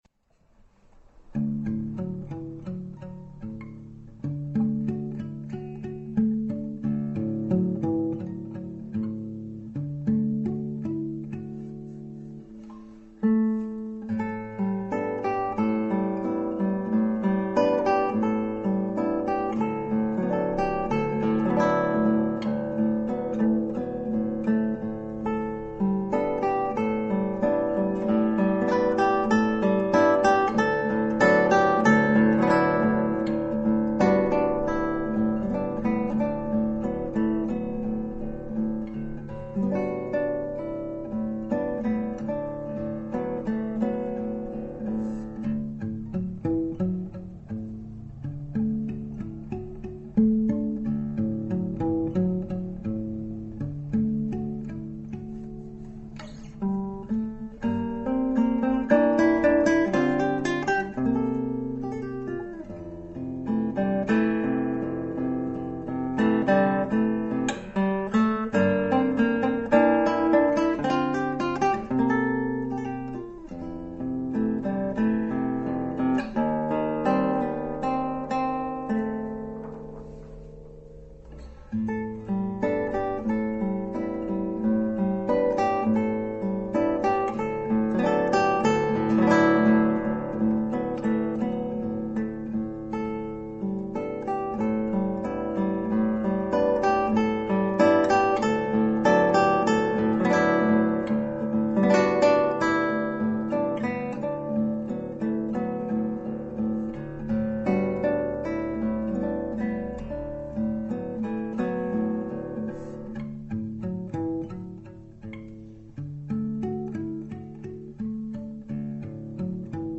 Chitarra Classica
melodia bellissima ed evocativa...